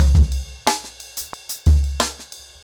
InDaHouse-90BPM.13.wav